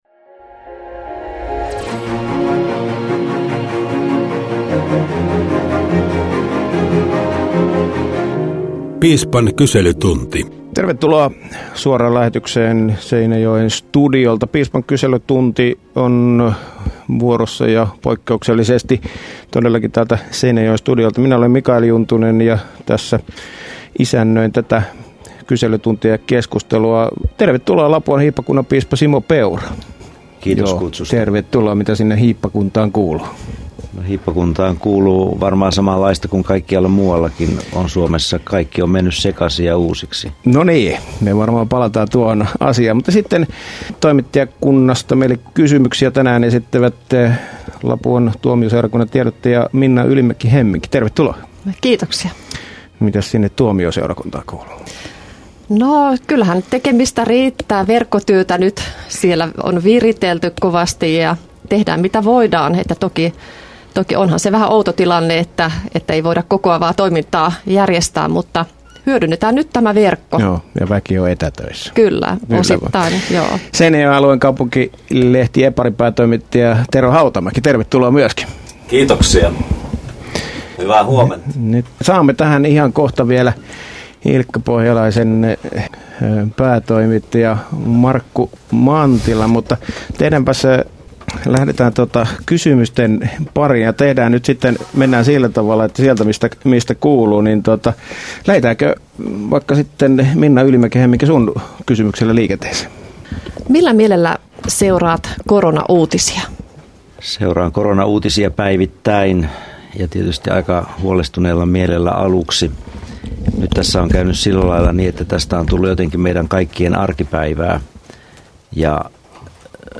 Keskiviikon suorassa lähetyksessä vastaajan paikalle asettuu piispa Simo Peura. Radio Dei lähettää kyselytunnin Seinäjoen aluetoimituksestaan.